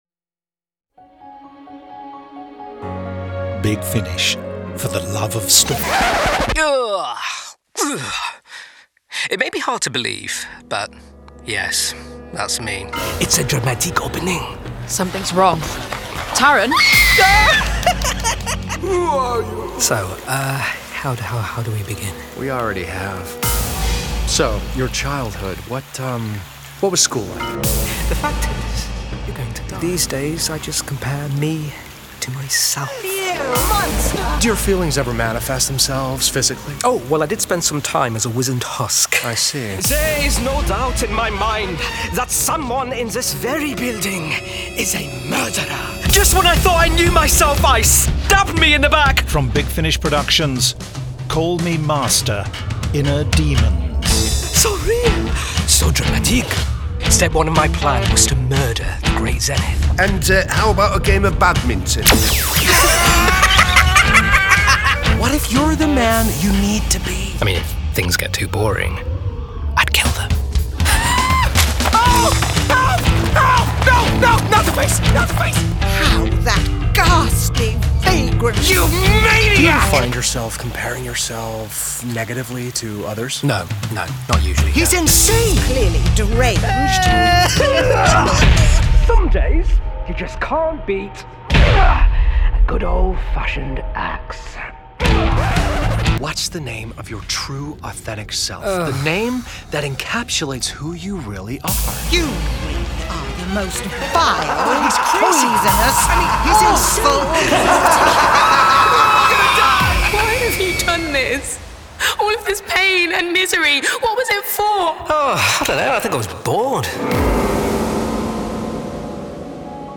Starring Sacha Dhawan